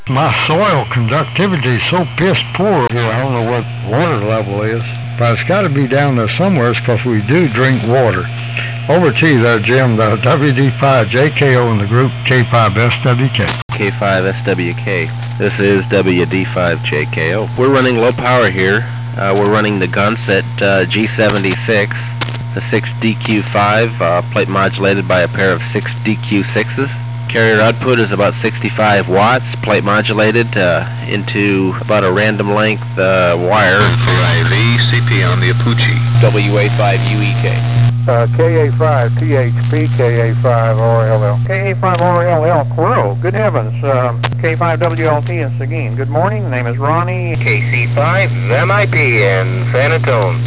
Hear The AM Boys fromTexas (544k)